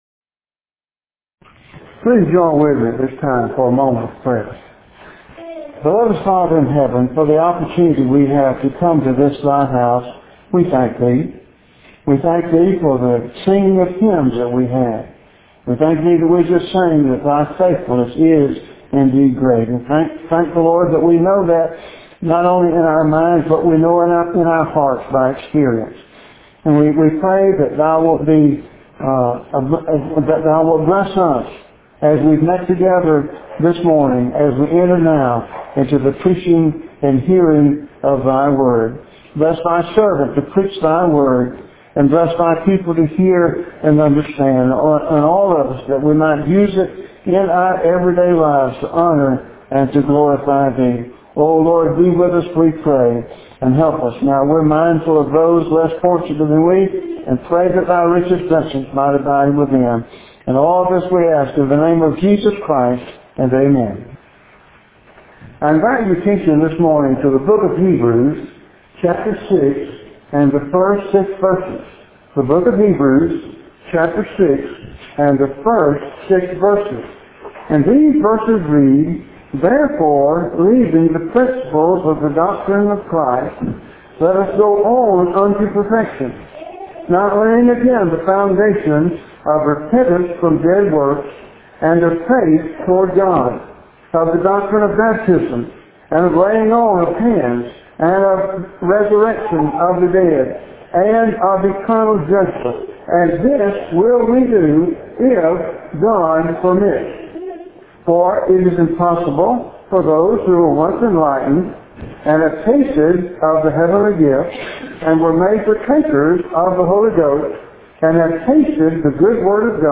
Hebrews 6:1-6, Under Christ Better Than Under The Law Dec 9 In: Sermon by Speaker